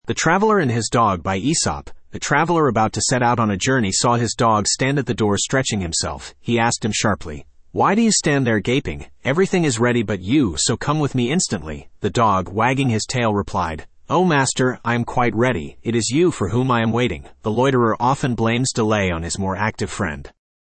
The Traveler And His Dog Spoken Ink « Æsop's Fables The Traveler And His Dog Studio (Male) Download MP3 A TRAVELER about to set out on a journey saw his Dog stand at the door stretching himself.